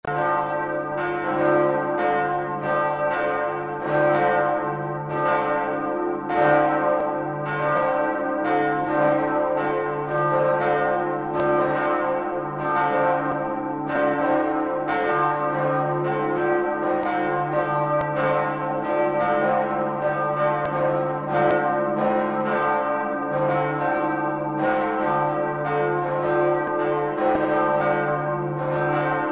Church bells
3-Gott-bells.mp3